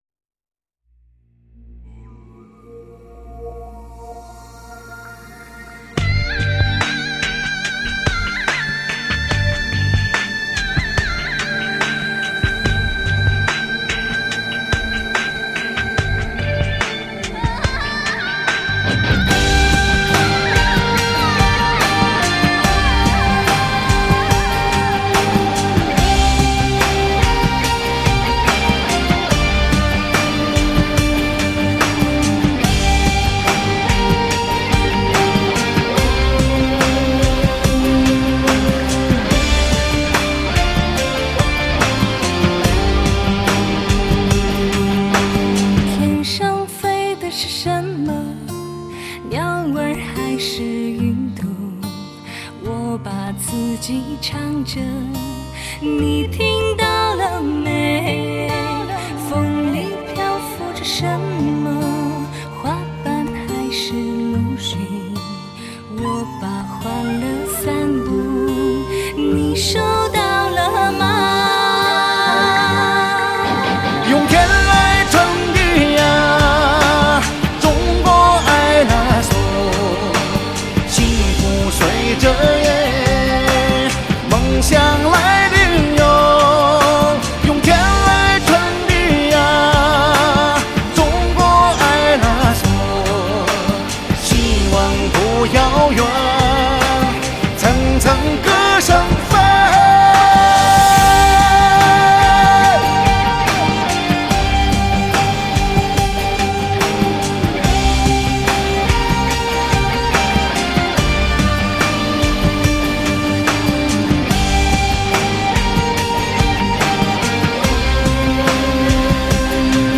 时尚民族风来袭 震撼心灵的天籁之音